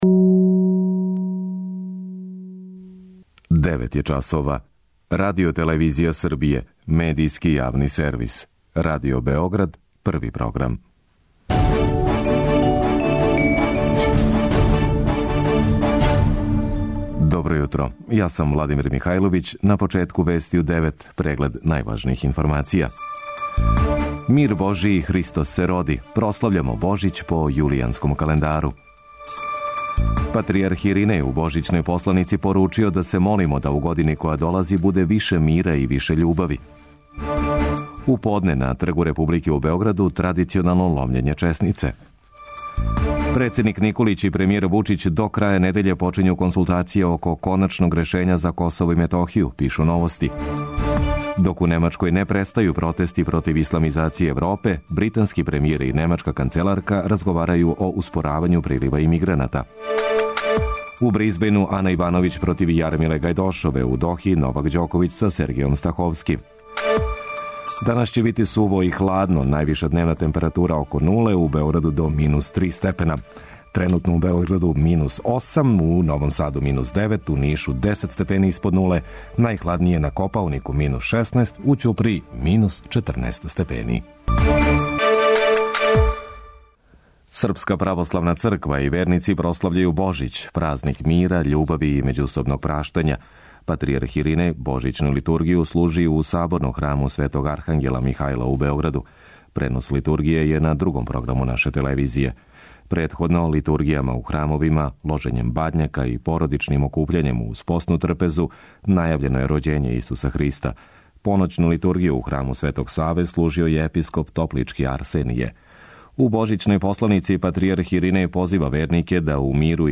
преузми : 8.36 MB Вести у 9 Autor: разни аутори Преглед најважнијиx информација из земље из света.